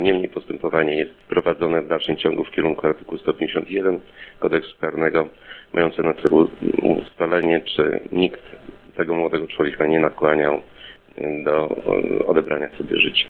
O działaniach śledczych, w związku ze śmiercią młodego chłopaka w placówce szkolno-wychowawczej w Ełku, mówi Jan Mikucki, Prokurator Rejonowy w Ełku.